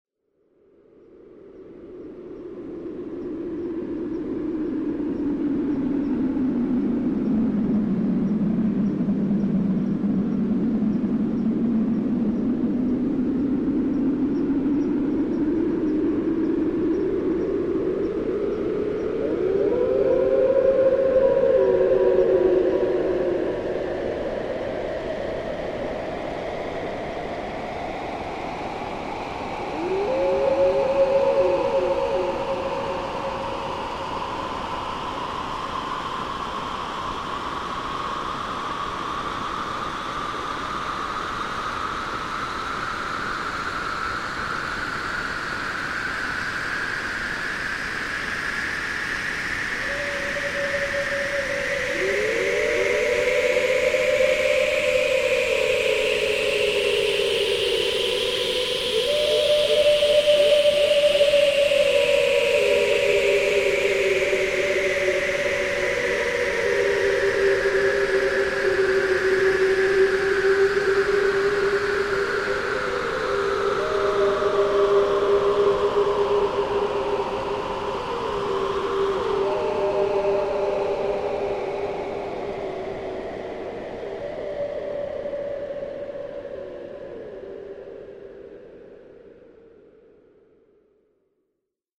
私の抱く「アルベルチーヌ」のイメージに非常に迫る音でありながら、それを更に超越した捉えようのない距離感も同時に備えている。
時に私に慰めを与え、時に挑発的に裏切る「毒」を含んだ緊迫感溢れる音の嵐が始まりも終わりもなく脳を直撃し、身体は熱を帯び浮遊し始める。